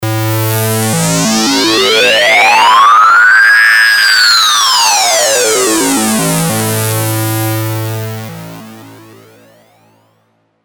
• Качество: 320, Stereo
Electronic
без слов